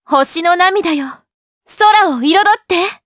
Laudia-Vox_Skill6_jp_b.wav